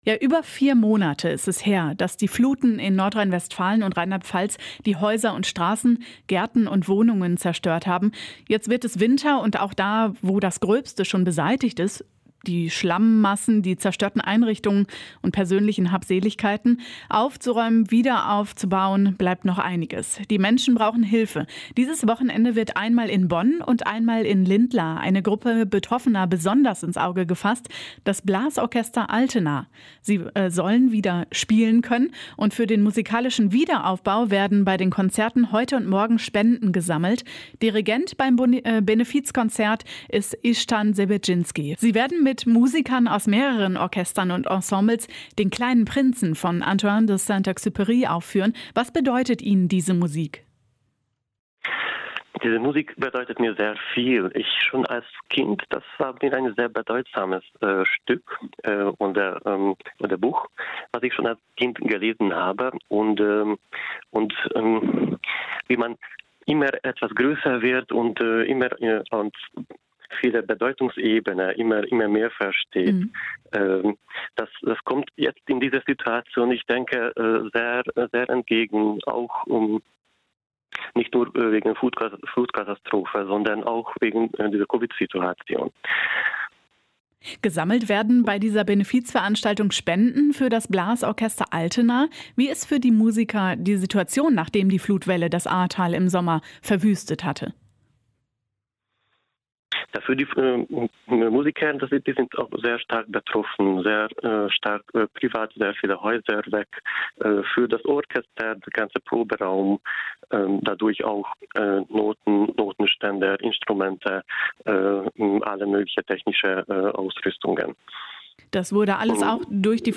Benefizkonzert für betroffene Musiker der Flutkatastrophe im Ahrtal - Ein Interview